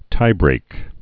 (tībrāk)